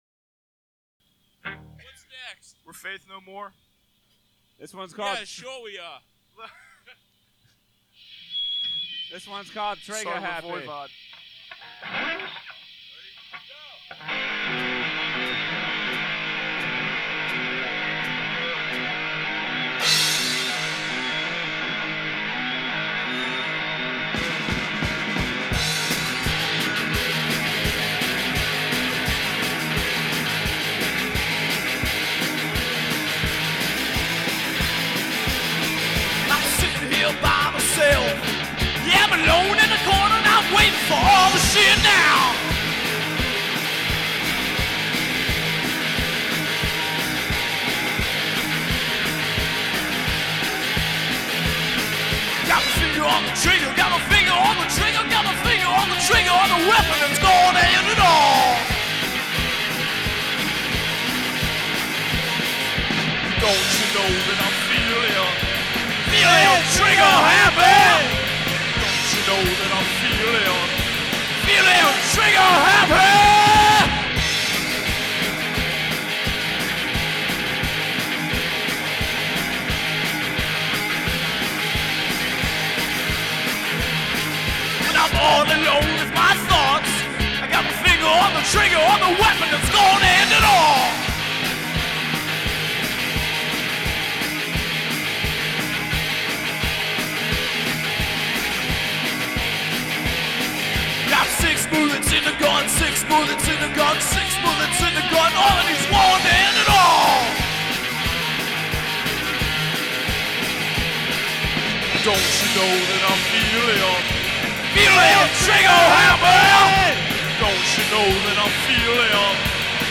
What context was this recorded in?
Live on WKDU 1/23/90